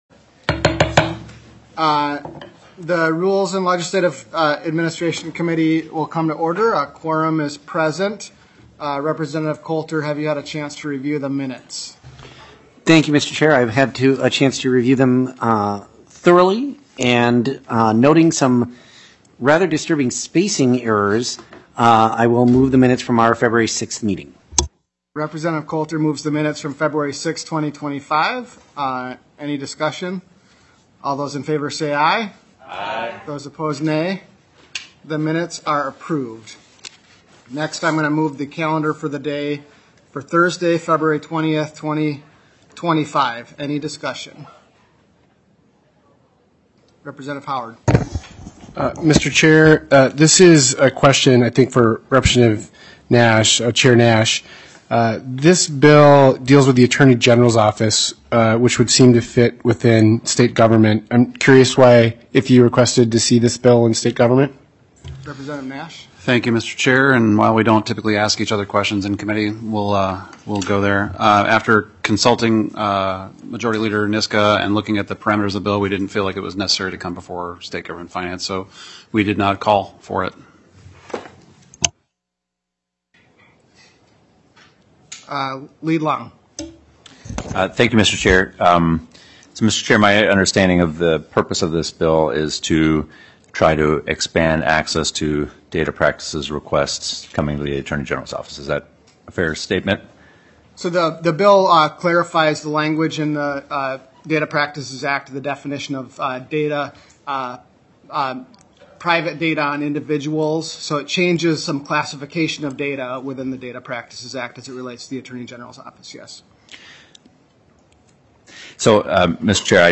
Majority Leader Niska, Chair of the Rules and Legislative Administration Committee, called the meeting to order at 2:48 P.M. on February 18th, 2025, in Room G3 of the State Capitol.